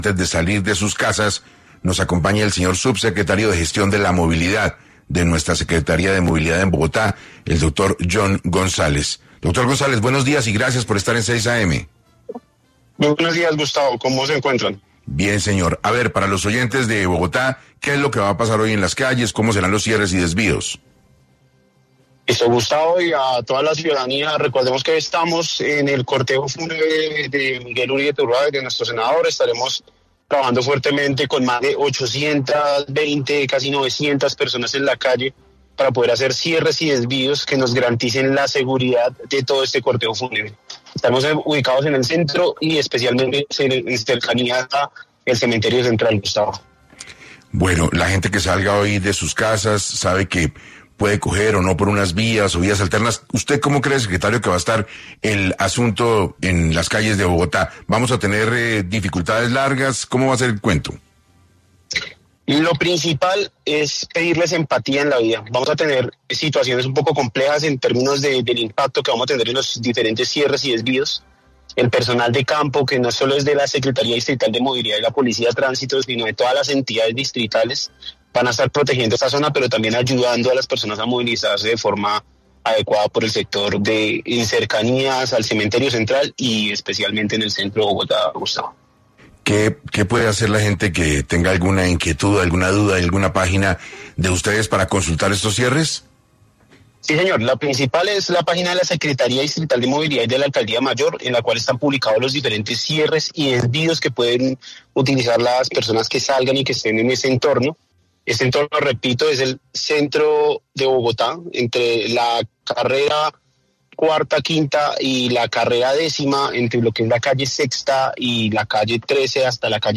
Subsecretario de Movilidad de Bogotá, Jhon González, estuvo en el programa 6AM de Caracol Radio, para explicar detalladamente cómo será el despliegue para la despedida del fallecido senador.